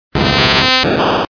Cri de Chrysacier dans Pokémon Diamant et Perle.